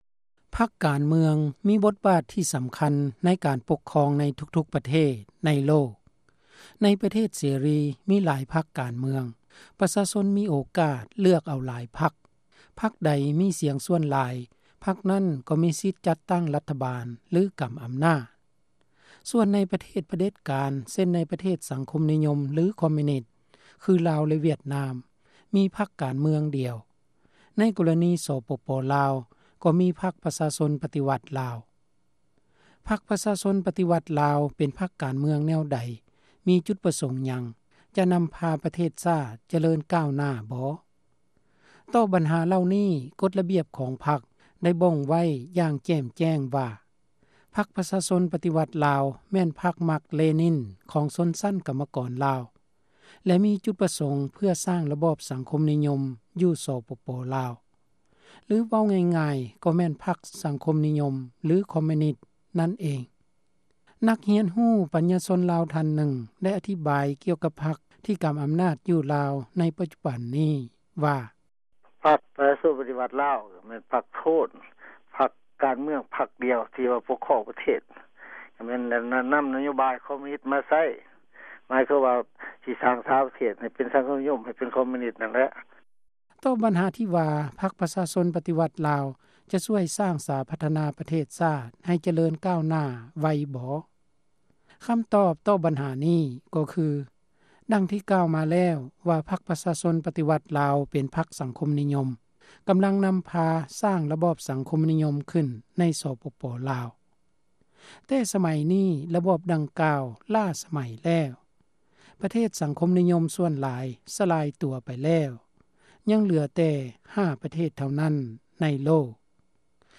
ບົດວິເຄາະ